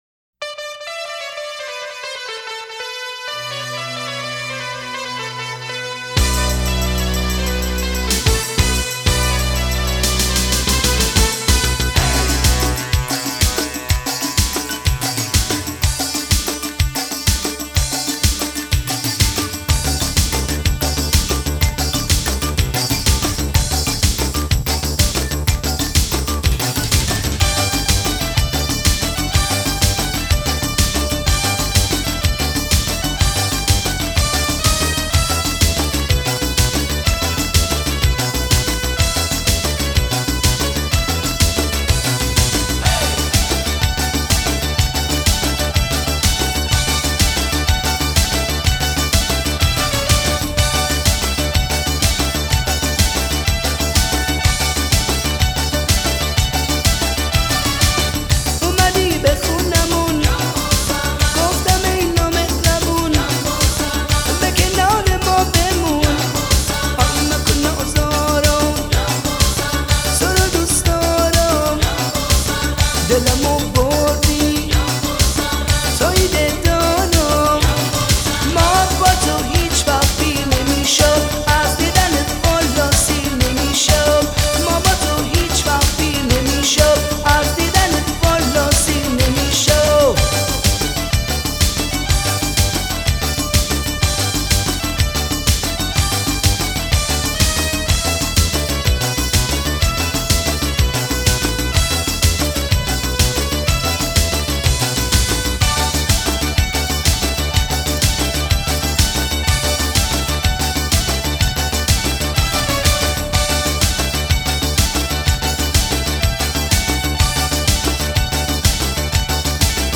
دانلود آهنگ شاد ایرانی